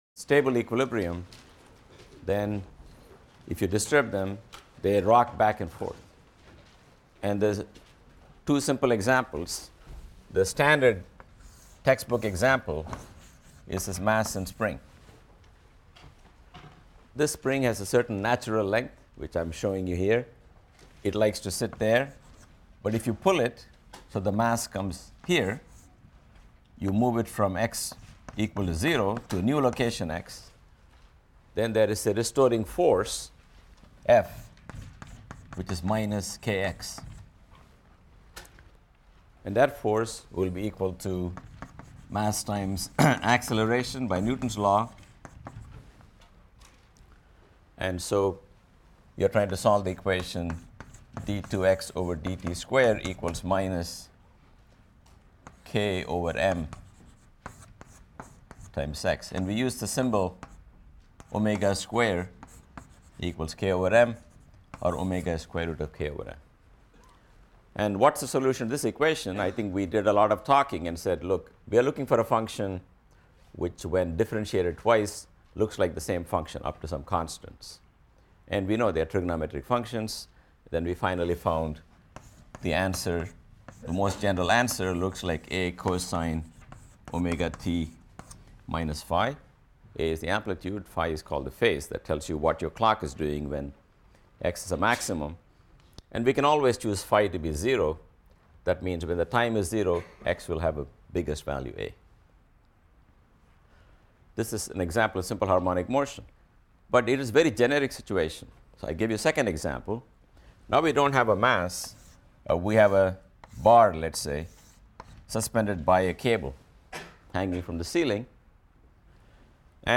PHYS 200 - Lecture 17 - Simple Harmonic Motion | Open Yale Courses